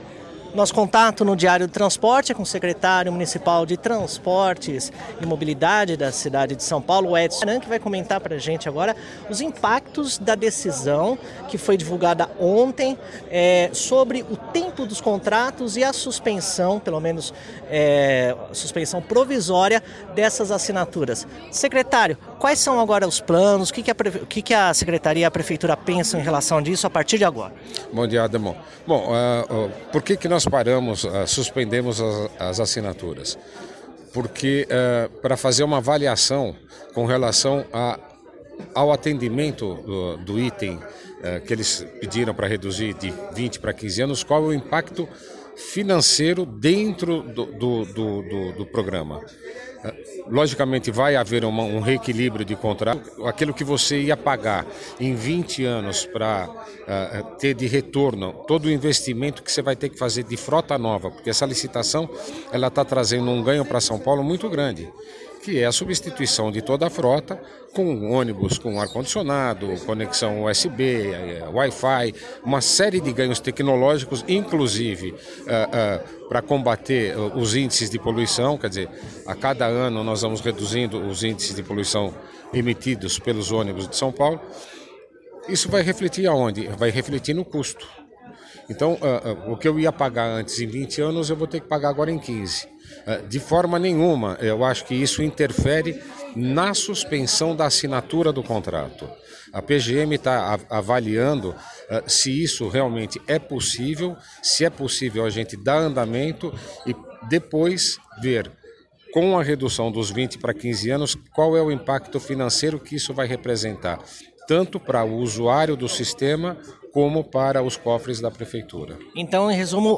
As declarações do secretário ao Diário do Transporte foram feitas durante o evento do Dia da Mobilidade Elétrica, evento promovido pela ABVE – Associação Brasileira do Veículo Elétrico, que representa fabricantes do setor.
Edson Caram, secretário municipal de mobilidade de São Paulo